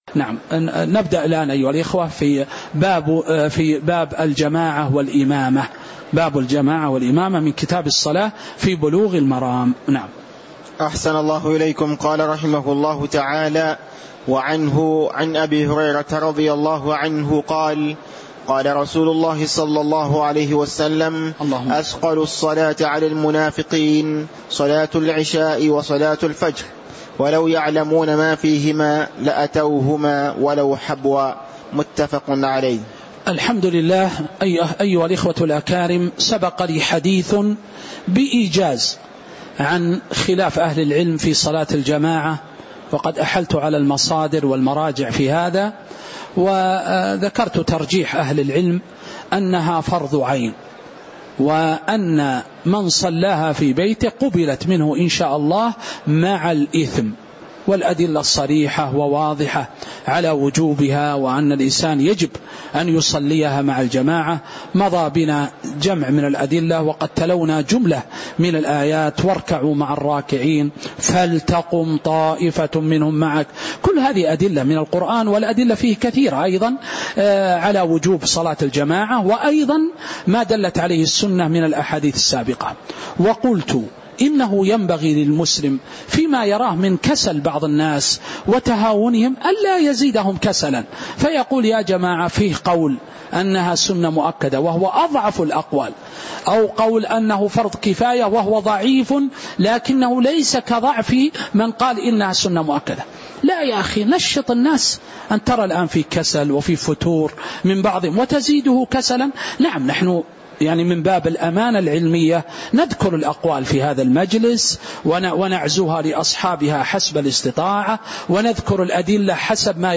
تاريخ النشر ٢٧ جمادى الأولى ١٤٤٥ هـ المكان: المسجد النبوي الشيخ